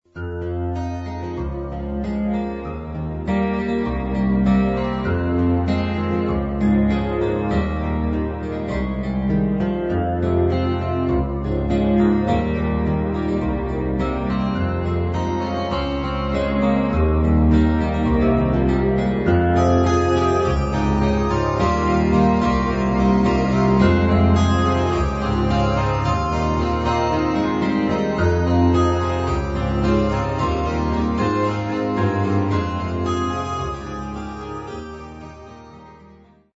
I played this just as a soundcheck to test if the recording setup was working, but I thought it was kind of cute and a nice intro, so in the spirit of gutsiness, here it is: